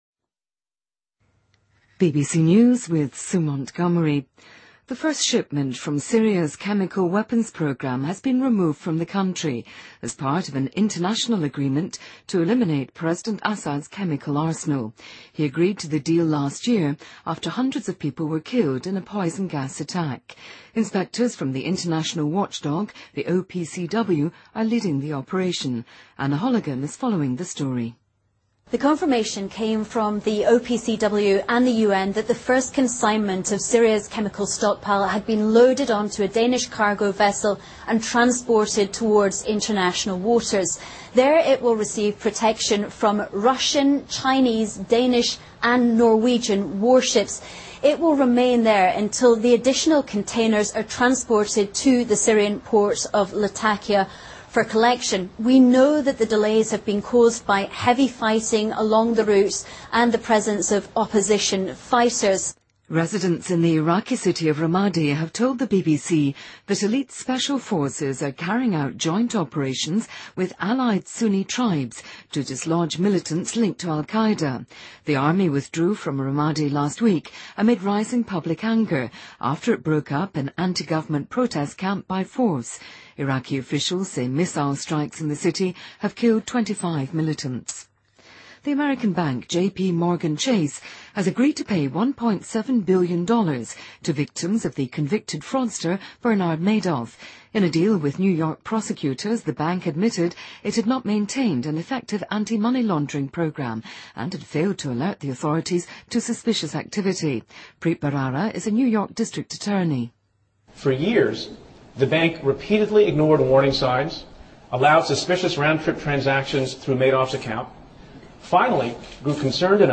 BBC news,美国摩根大通银行同意向诈骗犯纳德·麦道夫的受害者支付17亿美元